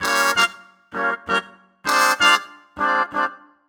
Index of /musicradar/gangster-sting-samples/130bpm Loops
GS_MuteHorn_130-GD.wav